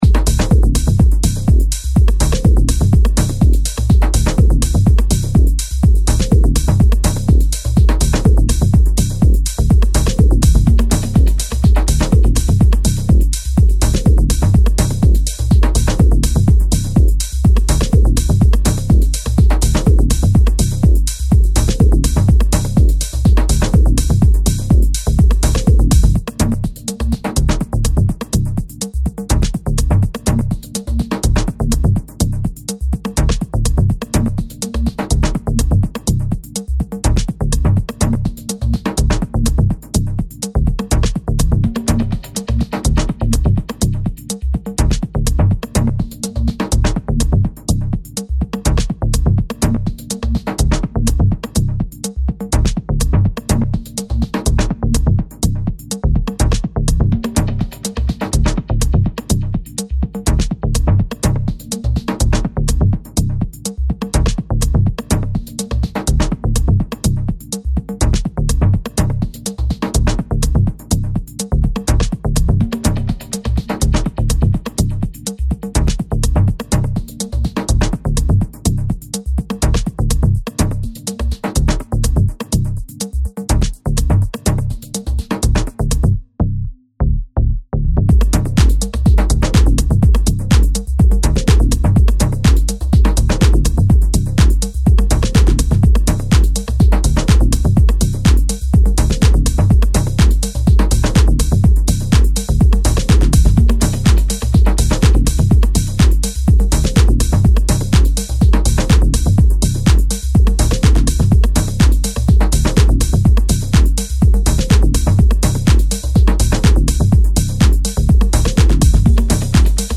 the dub version